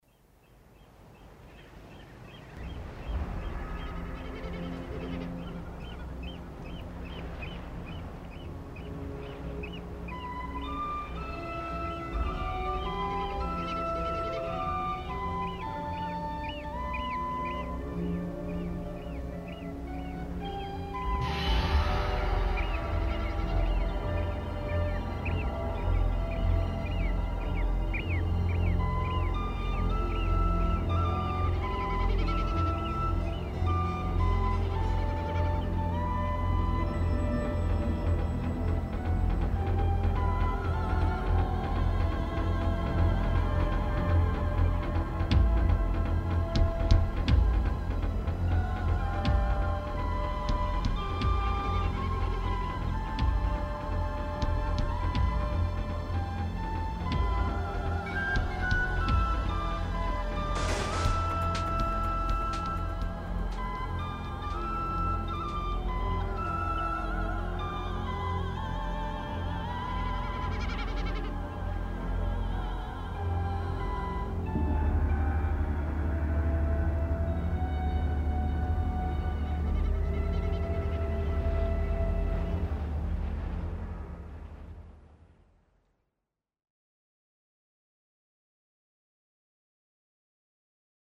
Whistle
Voice